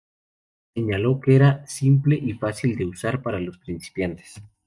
Pronounced as (IPA) /uˈsaɾ/